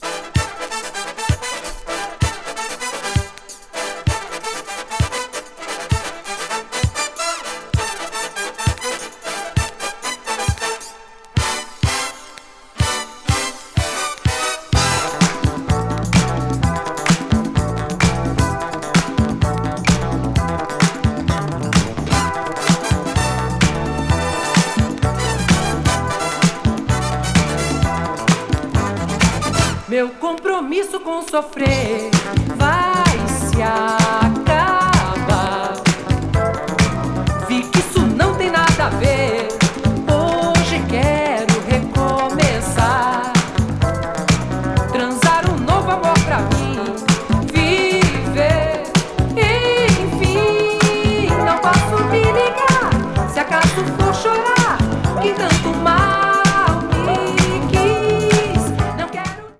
80s FUNKY LATIN / BRASIL MELLOW 詳細を表示する